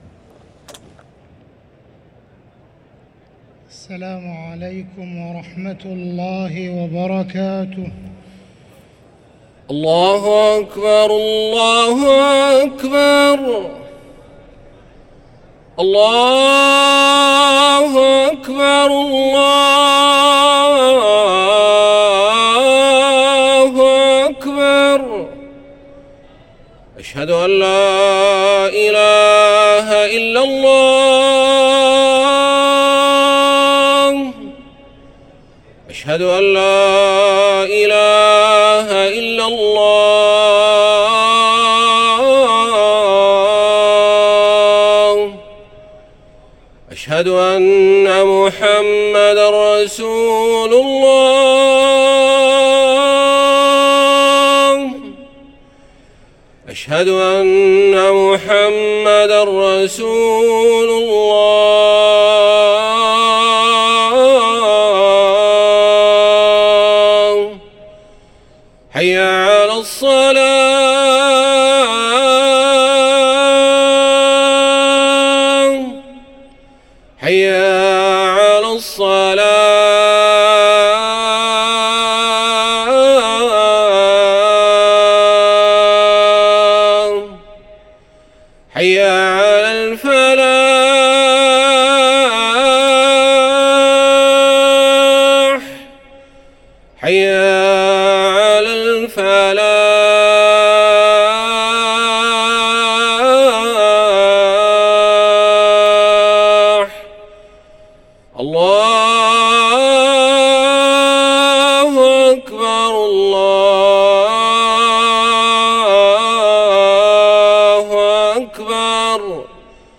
أذان الجمعة الثاني